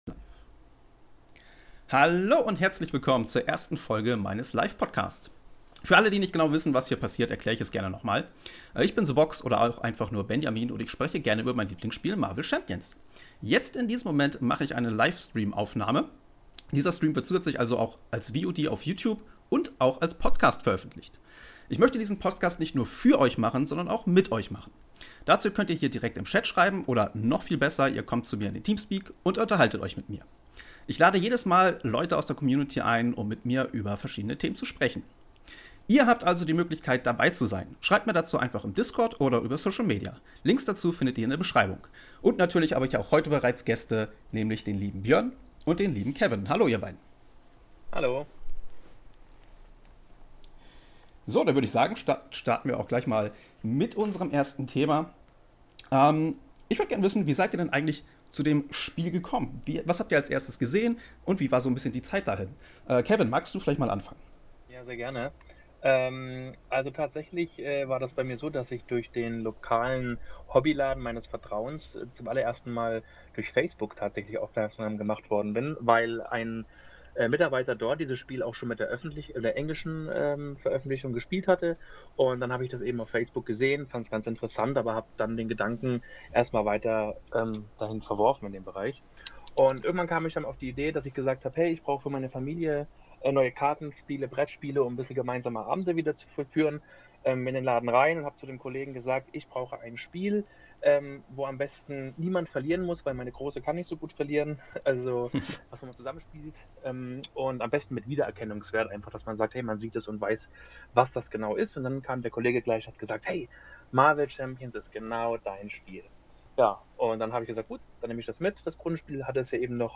Jetzt in diesem Moment mache ich einen Live-Stream.
Ich lade jedes mal Leute aus der Community ein um mit mir über verschiedene Themen zu sprechen.